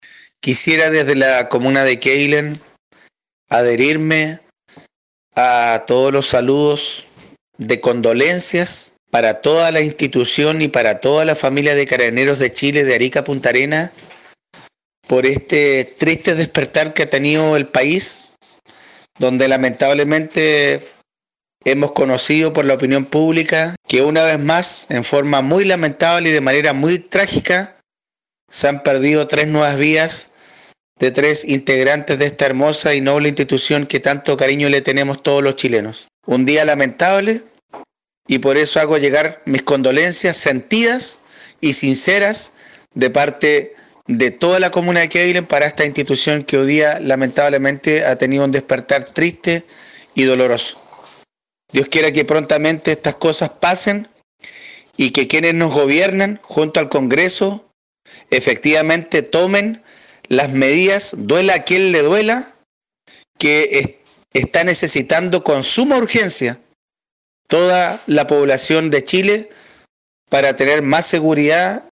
Por parte de la asociación de municipalidades de Chiloé, el alcalde de Quéilen, Marcos Vargas, manifestó sus condolencias a la entidad y requirió respuestas adecuadas de parte del gobierno y el parlamento.